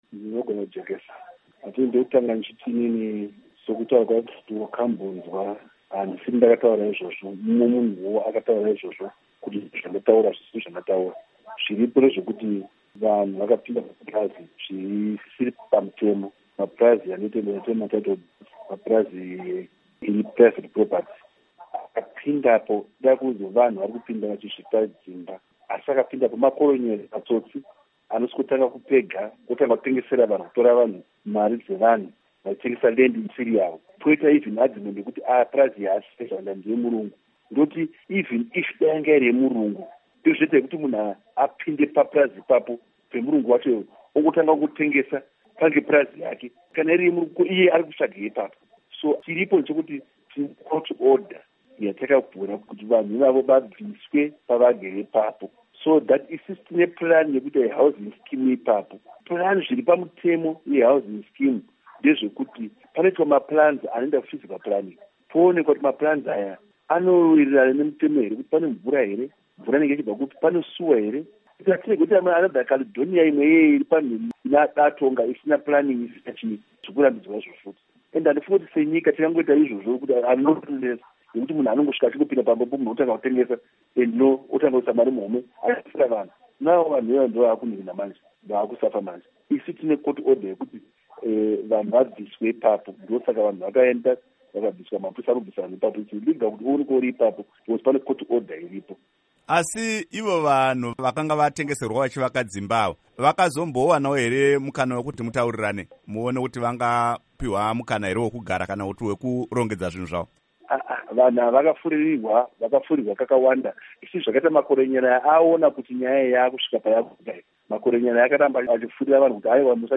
Hurukuro naVaPaddy Zhanda